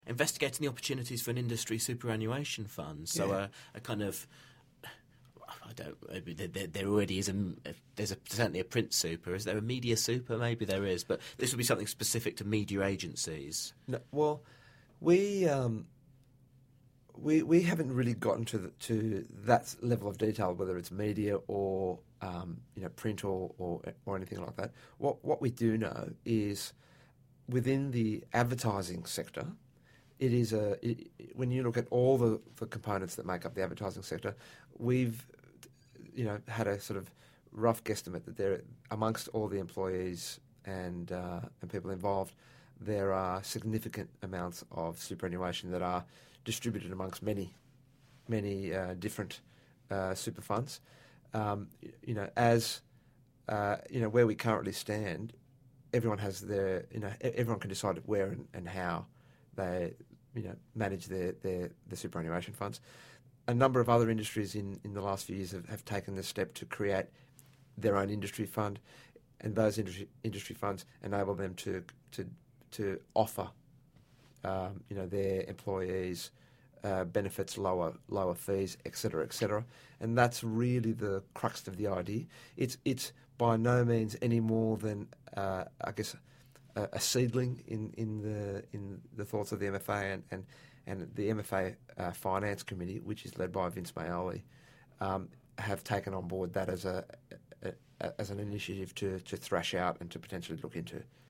In an interview with Mumbrella